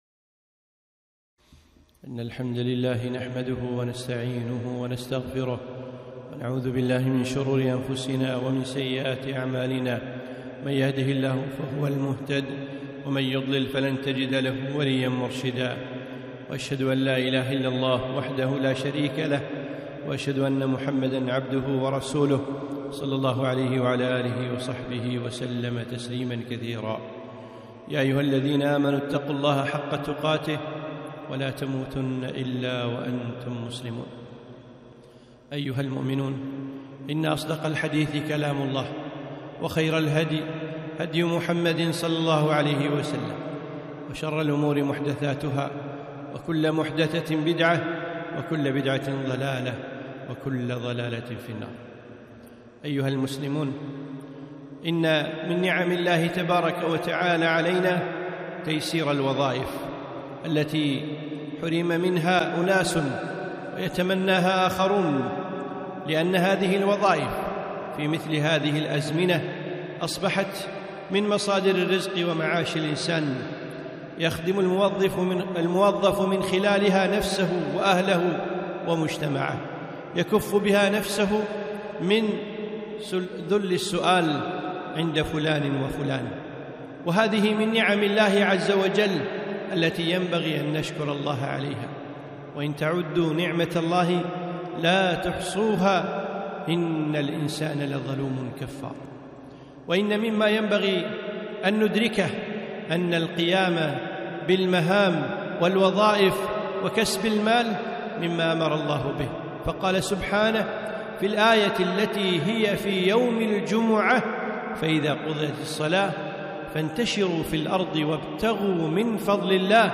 خطبة - أيها الموظف